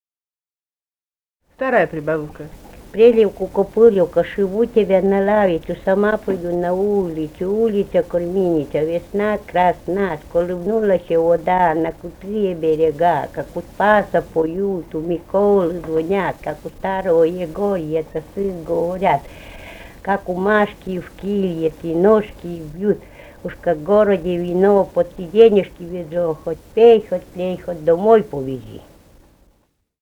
Файл:FCMC 016-136 Прельевка-копыльевка (прибаутка) И1131-29 Усть-Вотча.mp3 — Фолк депозитарий